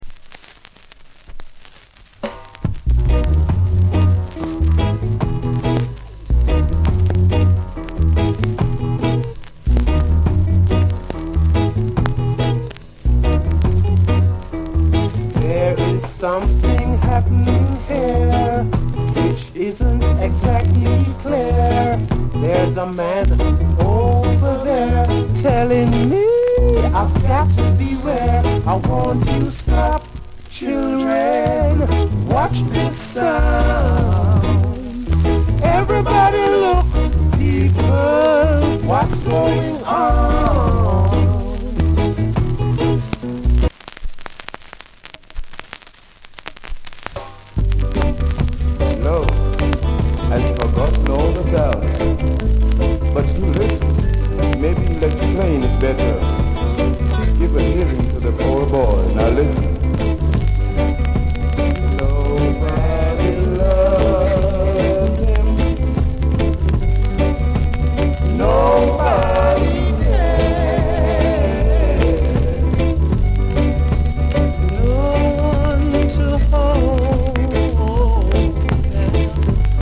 Notes: (heat bump at start)